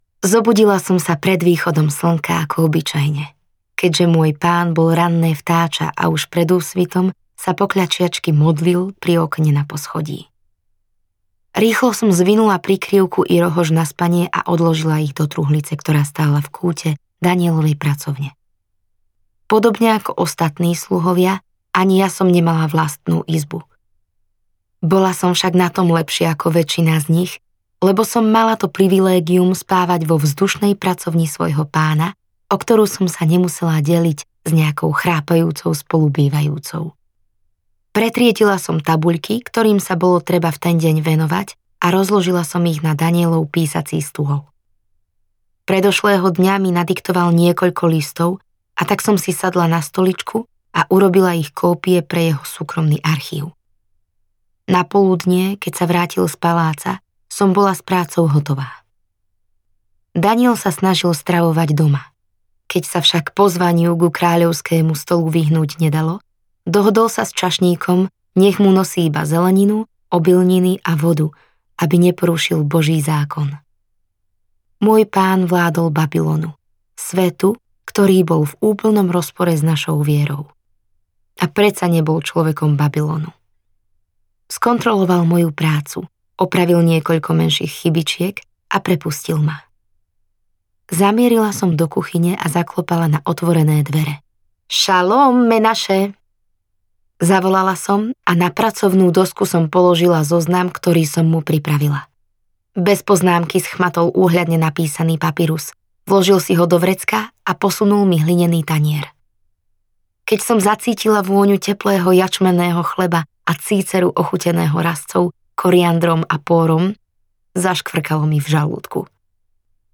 Skrytý princ audiokniha
Ukázka z knihy